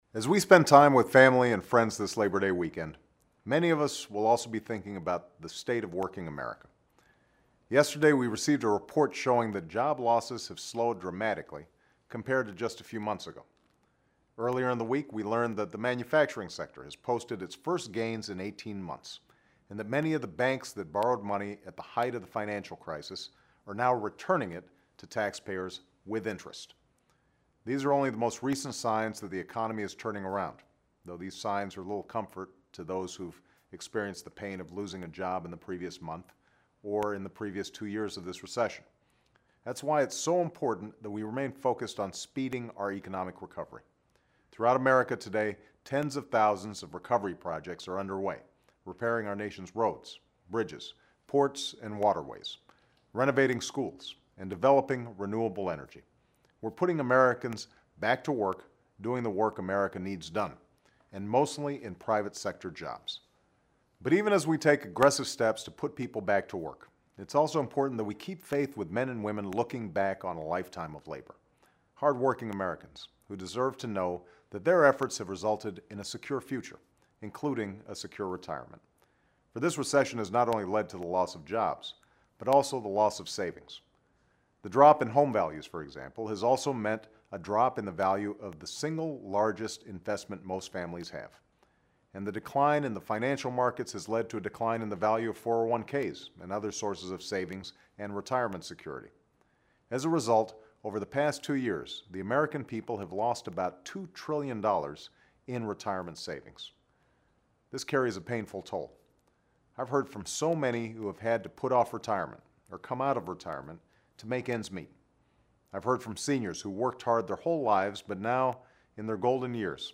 With Labor Day approaching, the President commits to rebuilding the economy so that a lifetime of hard work leads to a comfortable retirement.